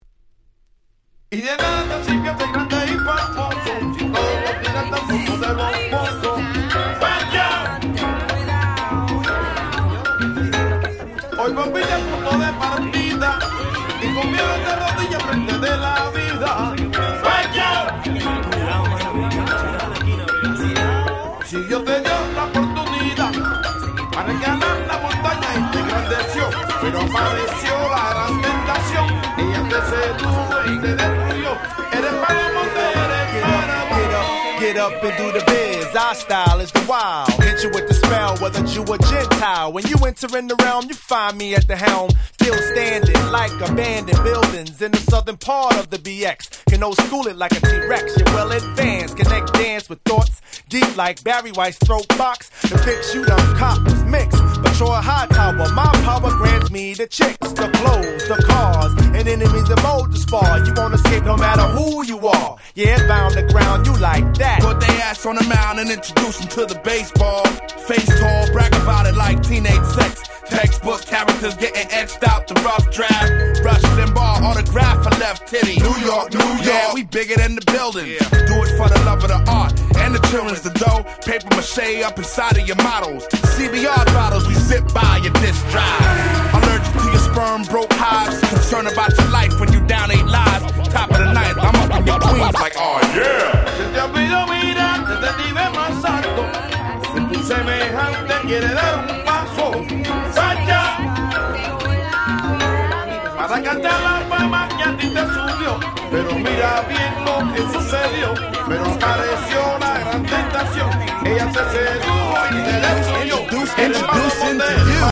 01' Super Nice Hip Hop !!